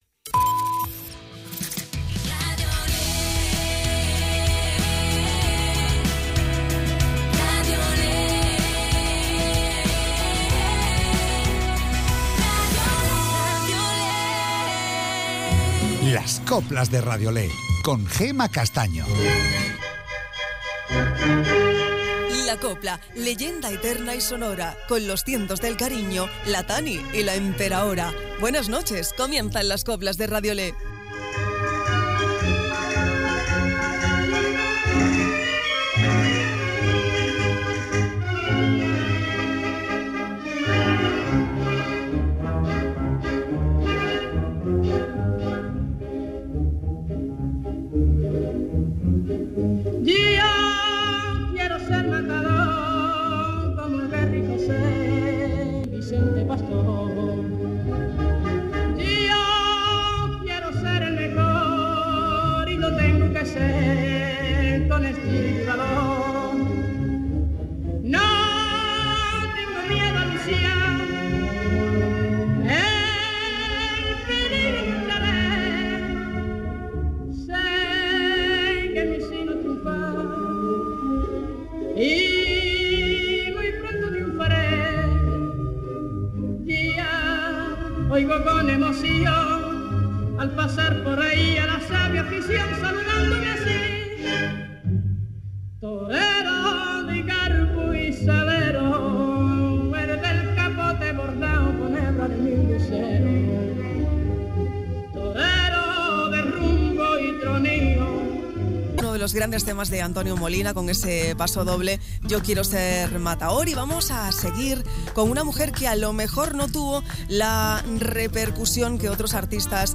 Las mejoras coplas de hoy y de siempre